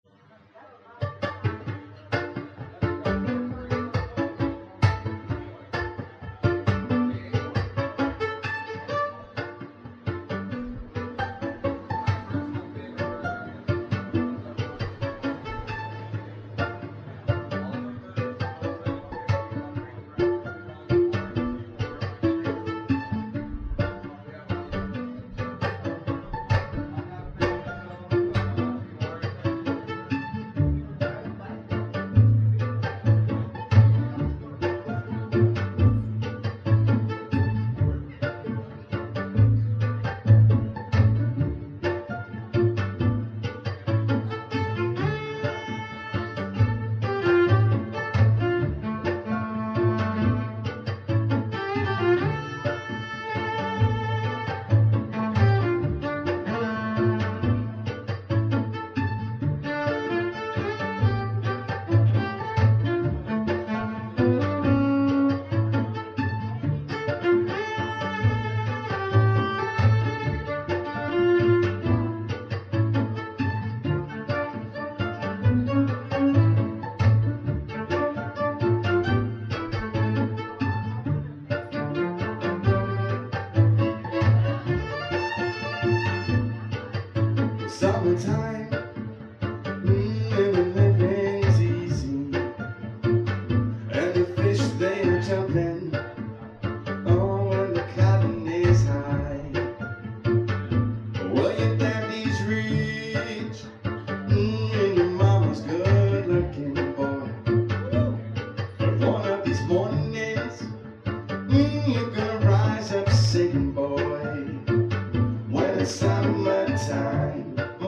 Jazz
Instrumental Cover